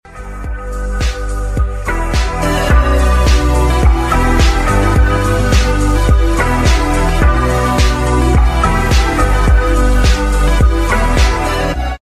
the quality sucks